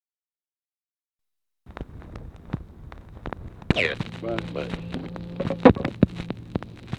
Conversation with UNIDENTIFIED MALE and UNIDENTIFIED MALE, April 20, 1964
Secret White House Tapes